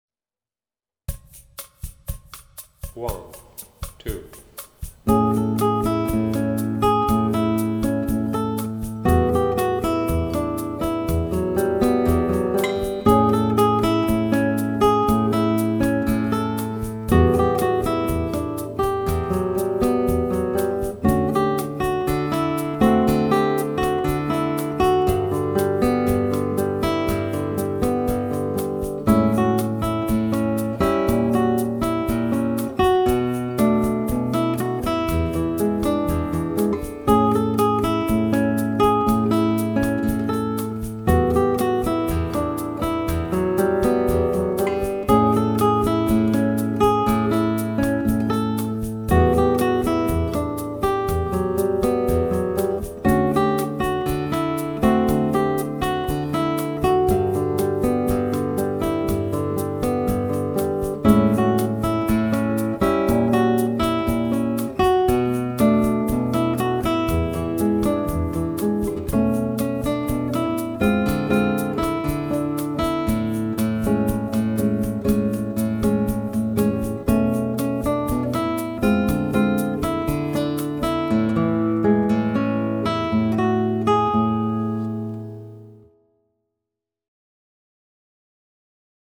Solo-Version in Normalgeschwindigkeit